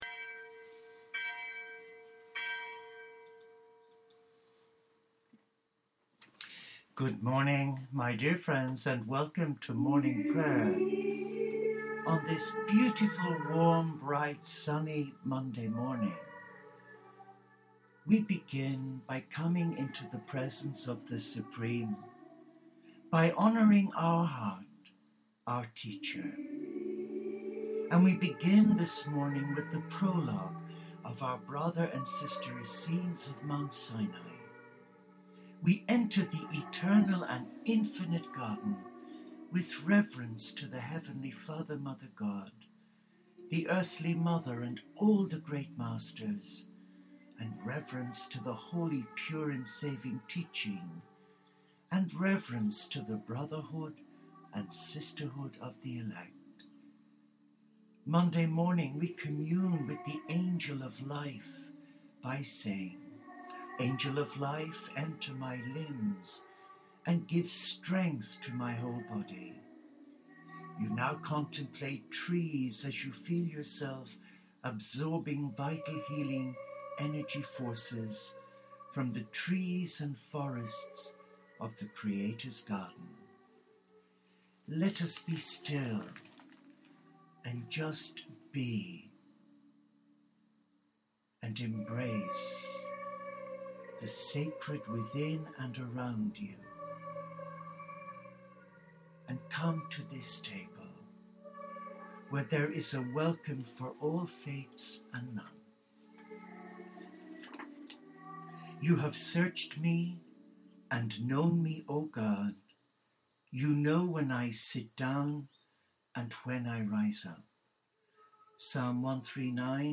Our video is a live recording Morning Prayer on Monday 10 march 4 The Whole Family of God in Crisis 2 Day.